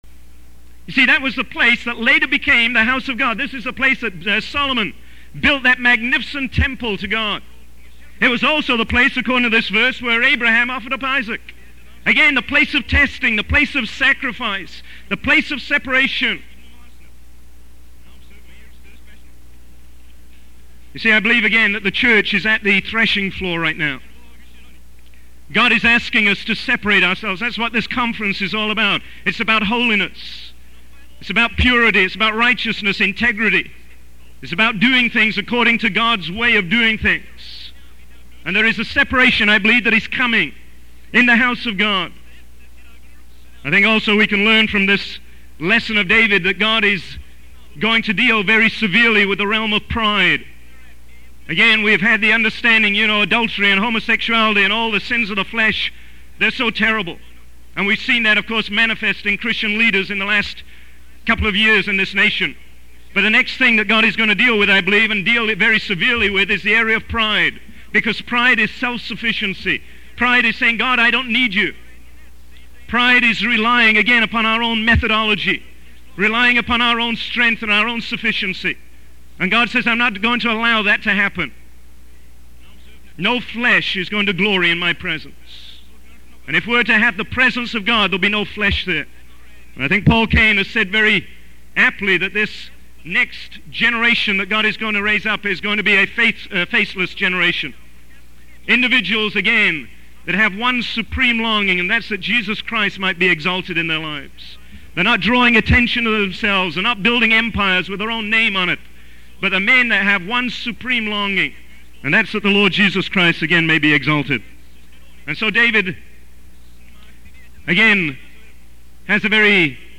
In this sermon, the speaker addresses the anger and setbacks that the people of God are experiencing. He believes that God is using these challenges to discipline and correct the church. The speaker emphasizes the importance of seeking God and doing things according to the divine order.